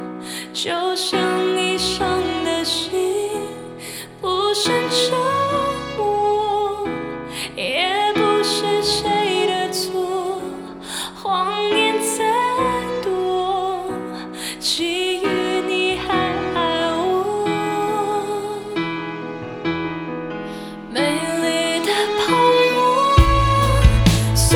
female.mp3